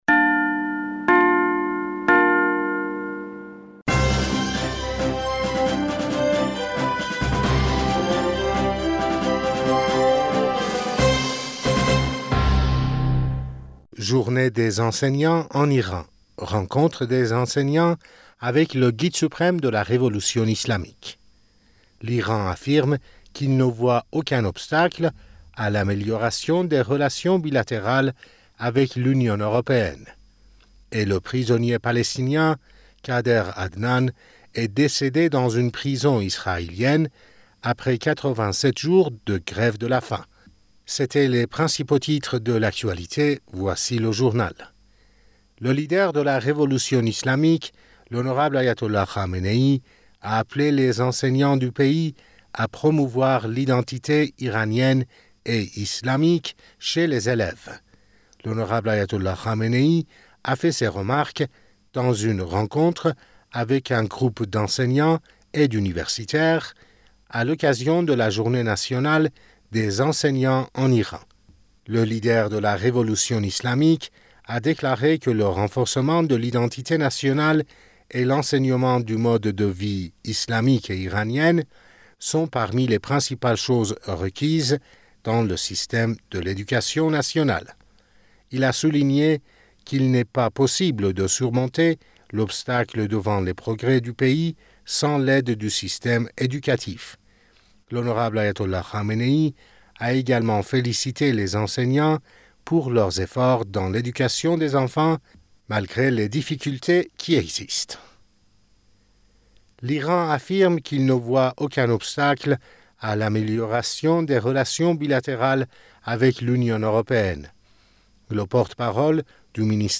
Bulletin d'information du 02 Mai 2023